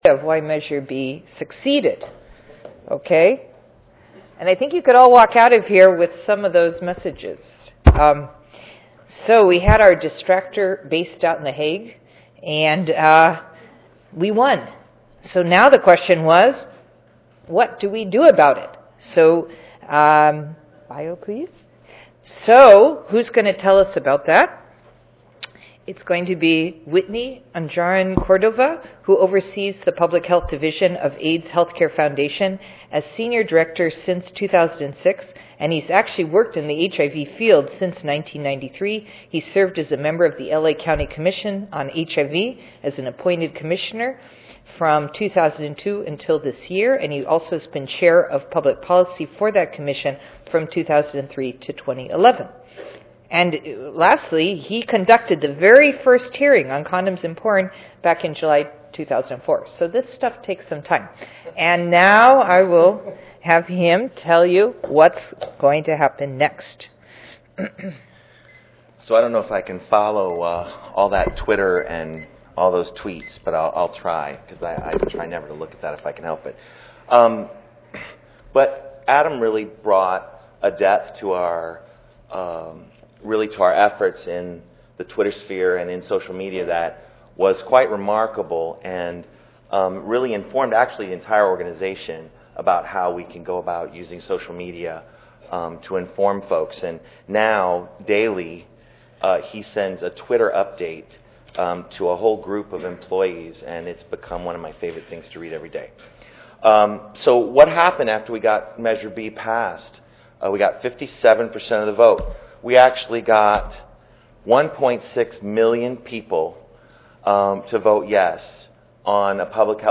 Oral Session